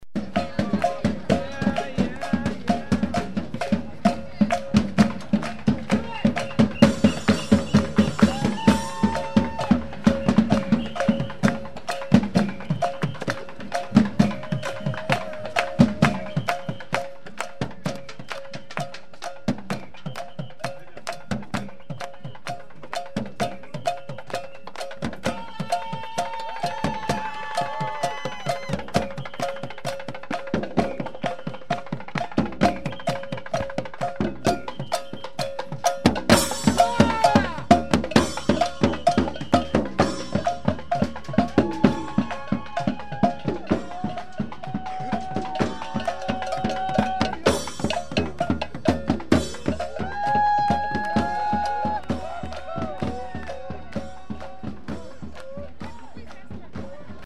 SF Antiwar Demo Sound 1
Quite a few drummers showed up. Here's one group's licks (:57)
drums1.mp3